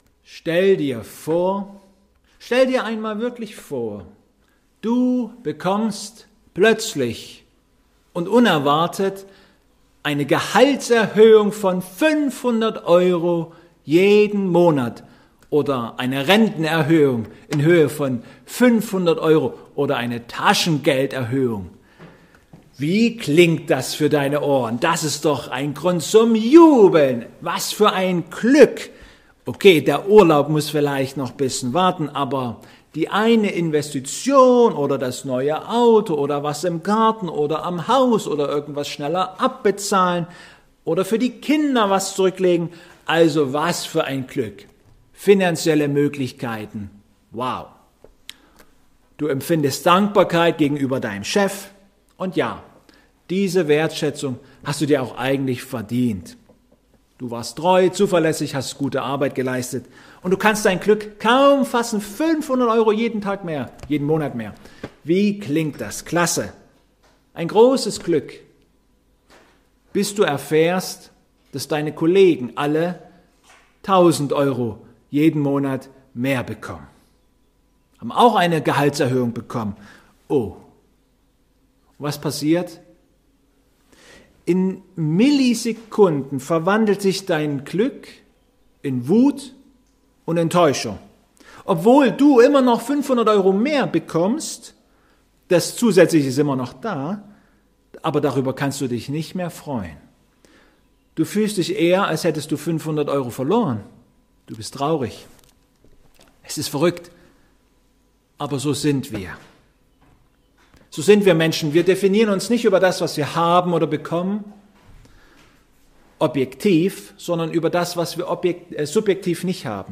Die Predigt zu Psalm 23,1b-3 kann man hier nachhören.
Ps 23,1-3 Gottesdienstart: Online-Gottesdienst Der Gottesdienst vom Sonntag Jubilate kann hier angeschaut werden.